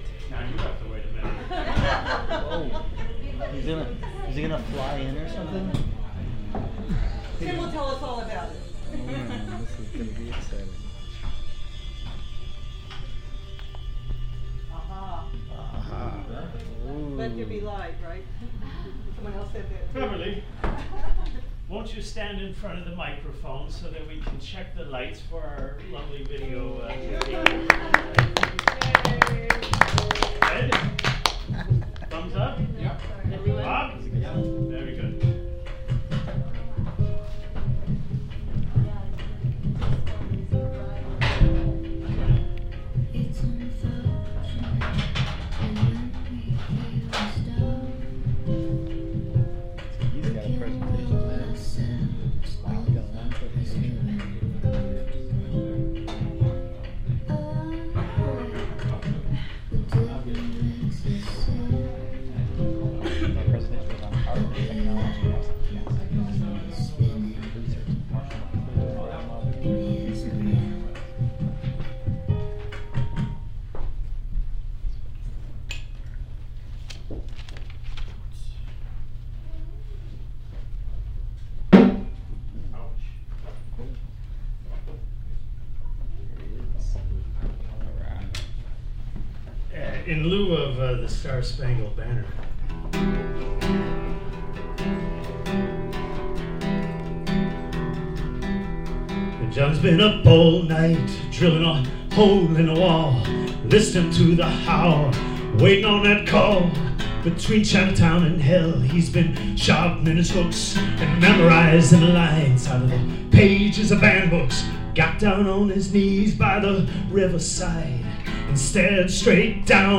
It's not often that we get to update the CMA audio podcast , but we're in good stead to say that we've got not one but two updates from the past month: (1) On November 5, 2011, the Human Thread art space in Pilsen played host to Media Democracy Day 2011 , the third such presentation in Chicago in as many years. We recorded the full audio of the opening panel , which features three current members of Chicago Media Action, and posted it in the podcast (about 75 minutes).
media_democracy_day_2011_panel.mp3